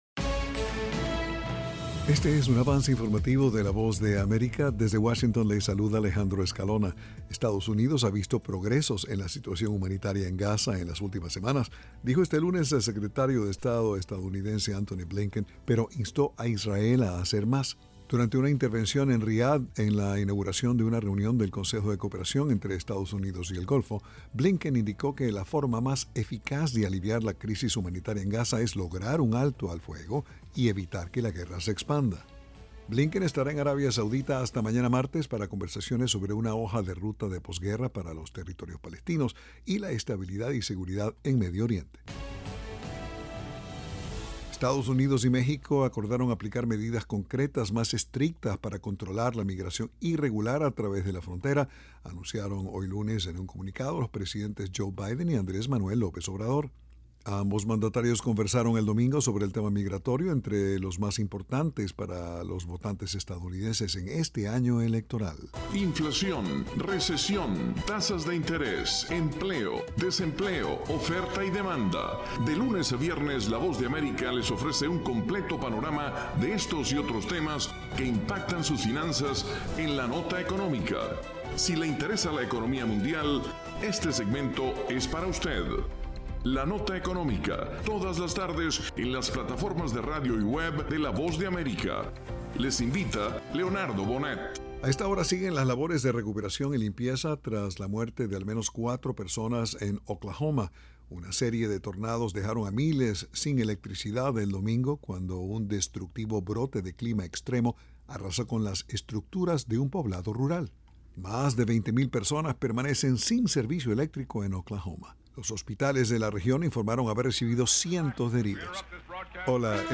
Este es un avance informativo presentado por la Voz de América desde Washington.